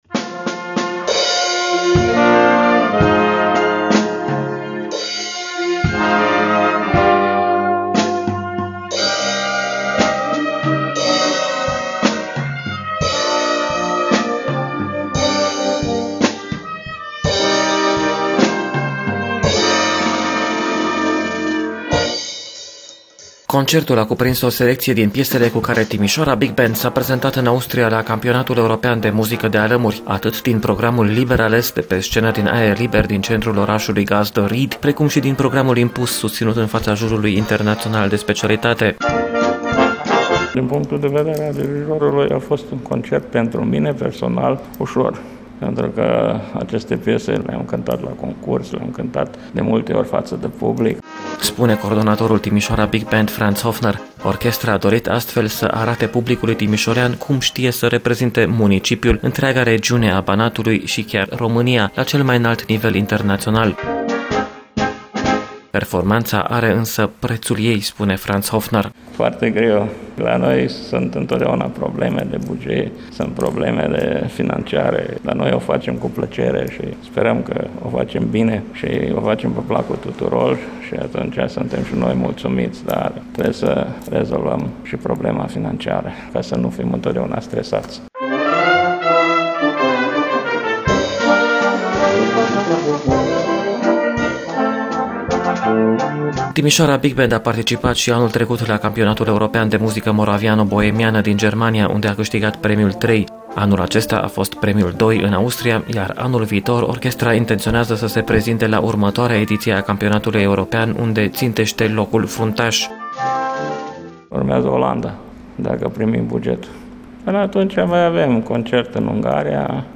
Timişoara Big Band, orchestra municipiului Timişoara, a susţinut, duminică seara, un concert în faţa publicului de acasă.